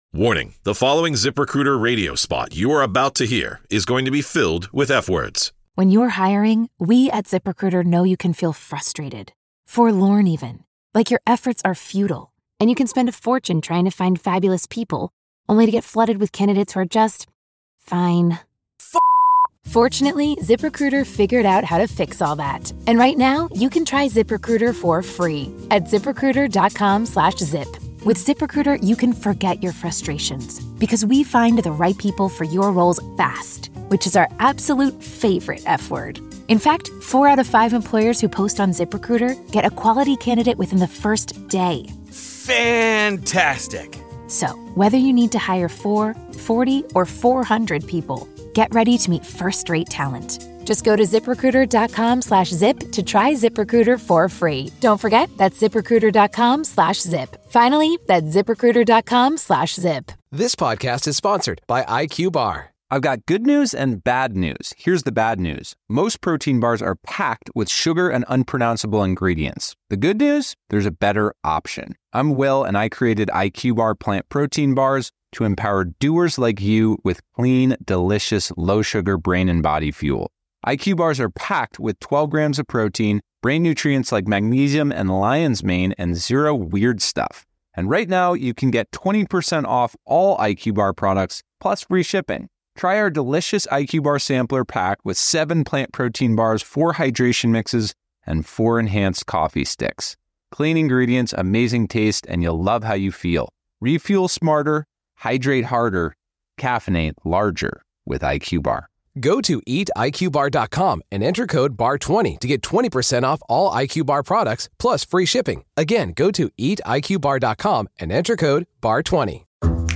Diddy Trial Juror Interview & Diddy Gets Standing Ovation in Jail